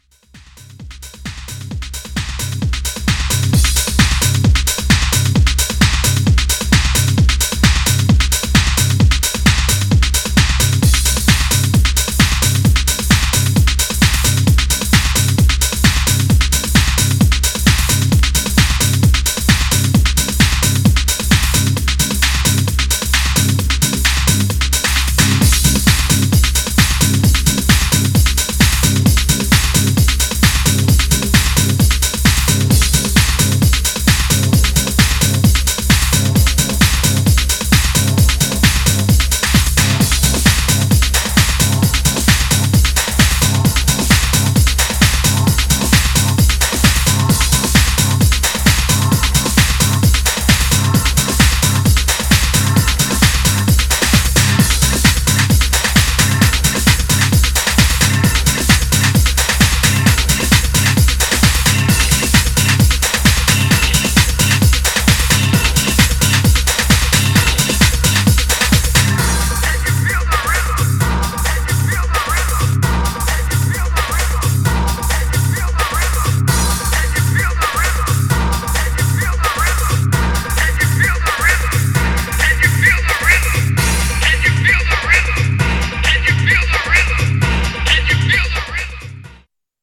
Styl: Progressive, House, Techno, Trance